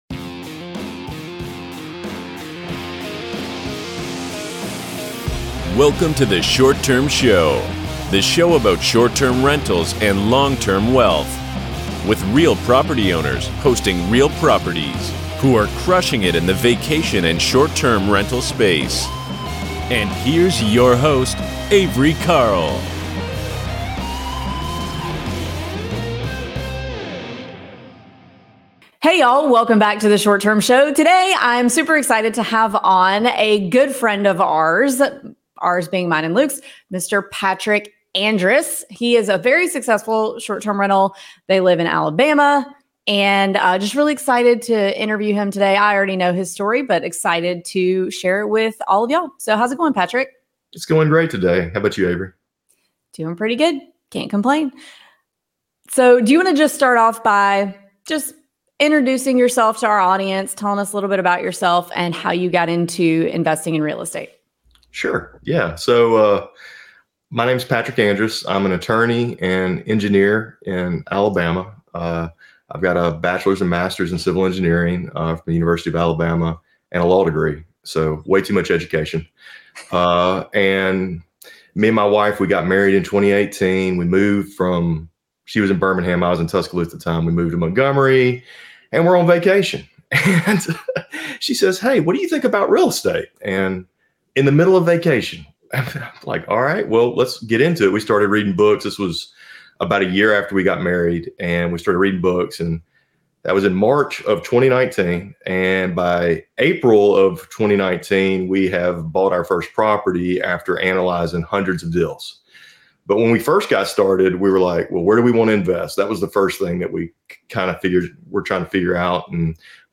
interviews successful real estate investors and CEO's of Airbnb related startups to bring inspirational and actionable content to listeners.